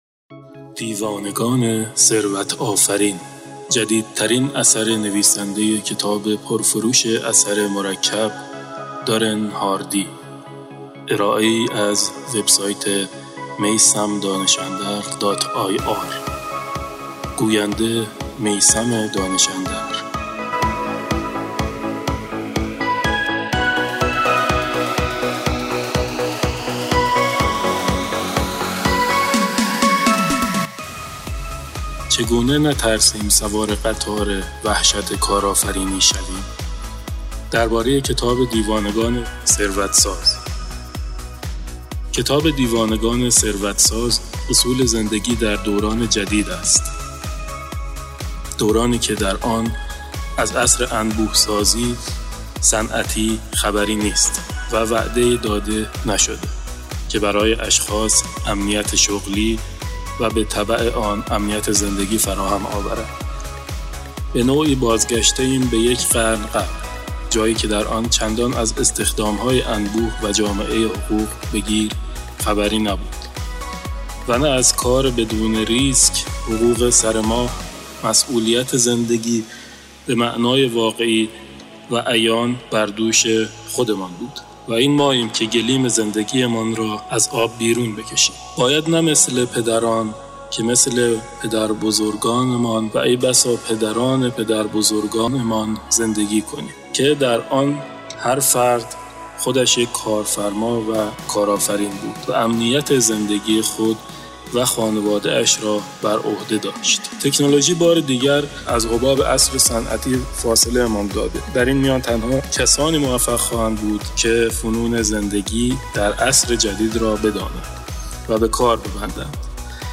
برگرفته از کتاب دیوانگان ثروت ساز اثر دارن هاردی ضبط شده در استودیو
دارای موسیقی انرژی‌بخش پس‌زمینه. یک کتاب صوتی انگیزشی برای سوار شدن بر قطار وحشت کارآفرینی.